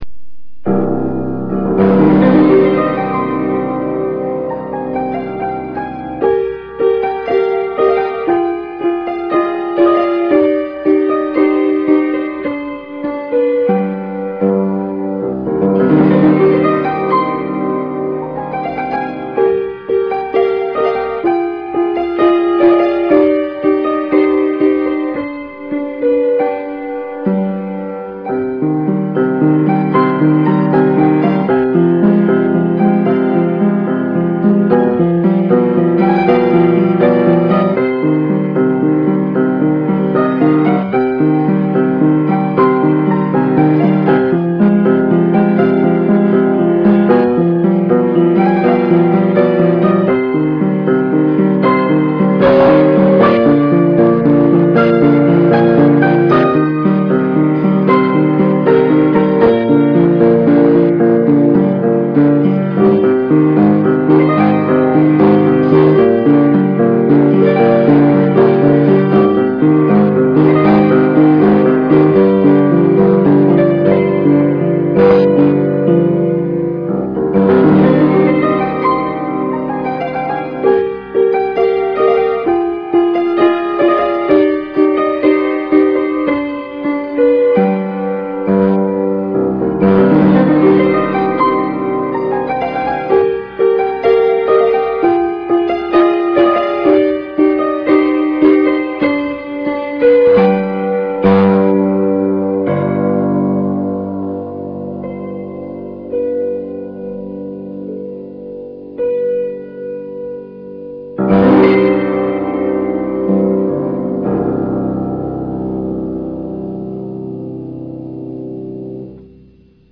Classical:
Prelude for Piano in C Minor.
PianoCm.RAM